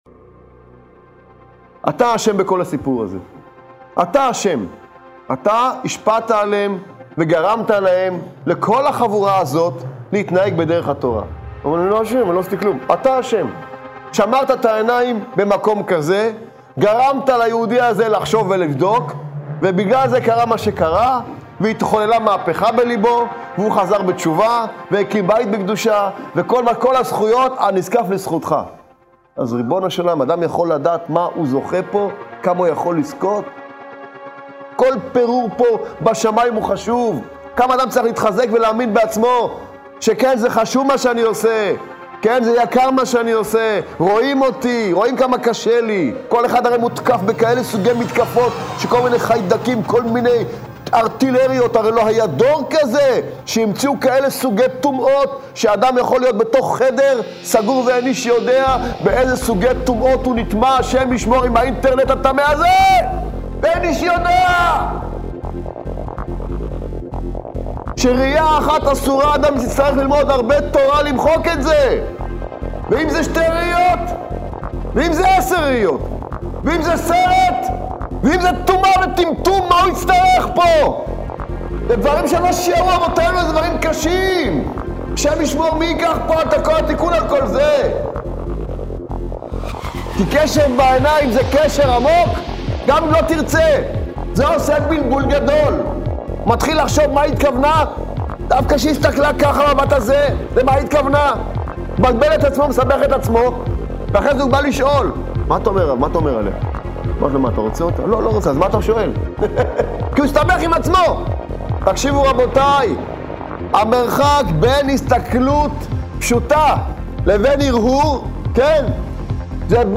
שיעורי שמע